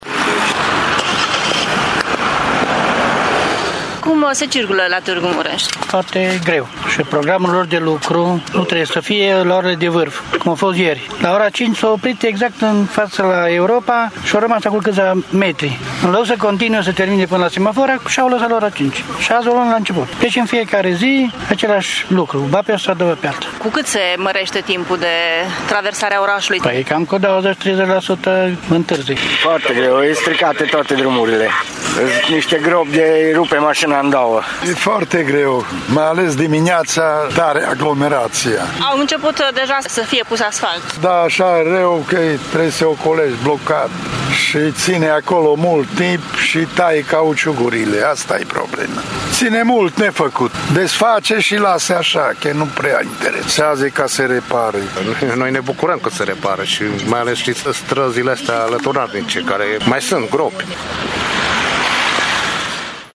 Șoferii se plâng că străzile au fost decapate și lăsate zile întregi, până la plombare: